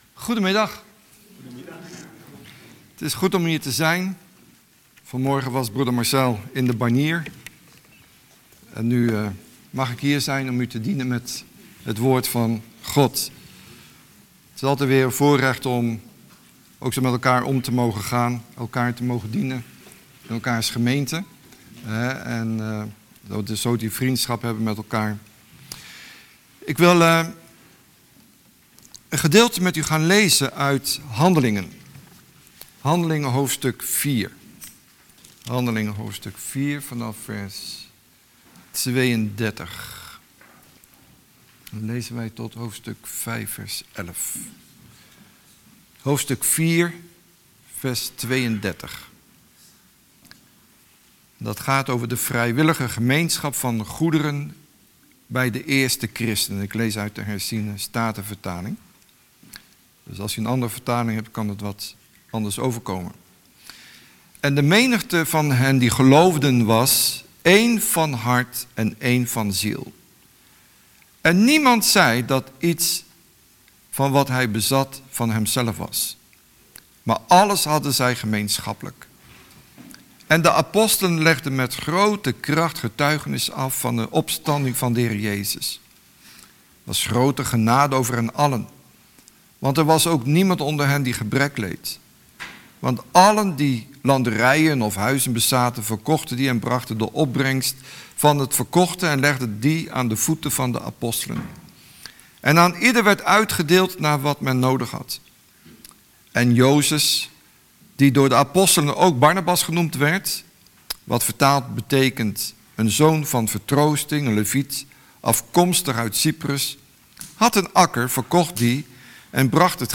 Preek